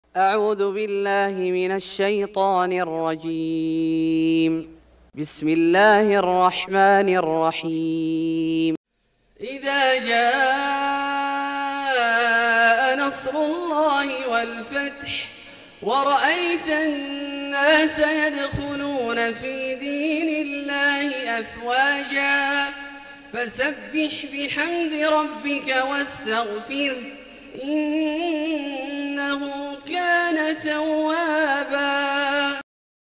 سورة النصر Surat An-Nasr > مصحف الشيخ عبدالله الجهني من الحرم المكي > المصحف - تلاوات الحرمين